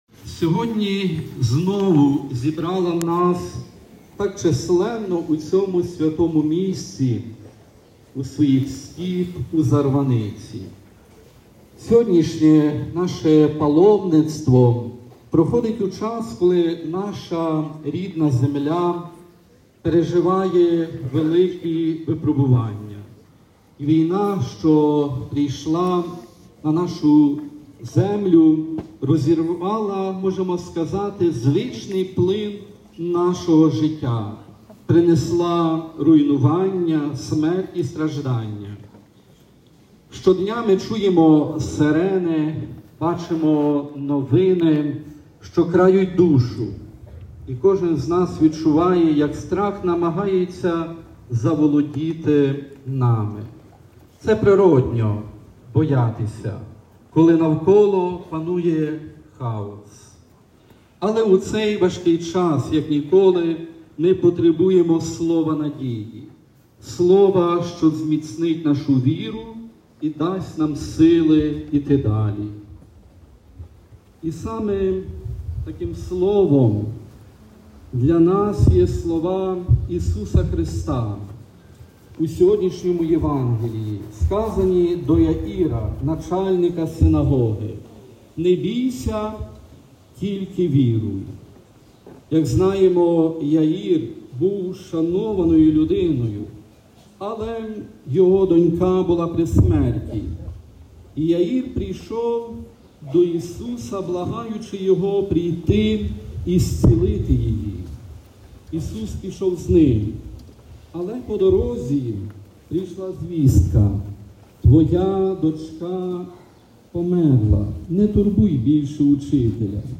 Сотні паломників зібралися сьогодні, 19 липня, на Божественну Літургію біля парафіяльного храму в селі Зарваниця у рамках Всеукраїнської прощі.
propovid-vladyky-ivana-kulyka-na-proshhi-u-zarvanyczi.mp3